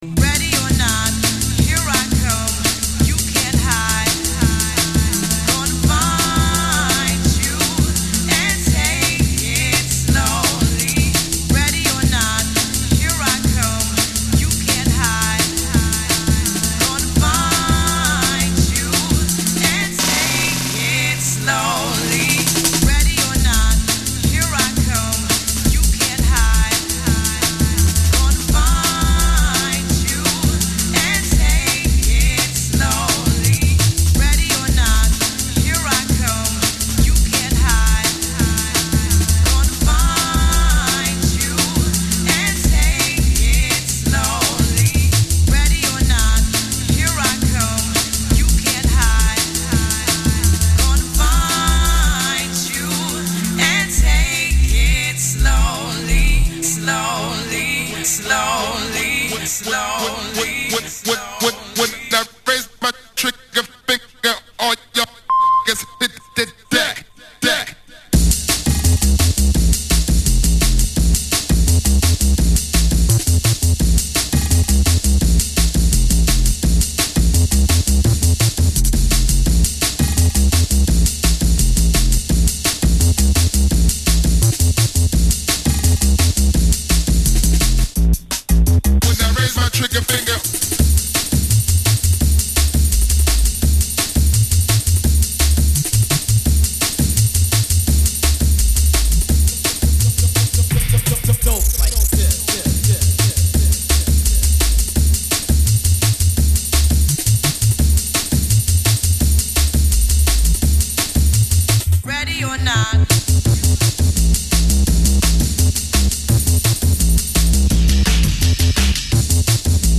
rolling classic club tunes
-Mainly house, dnb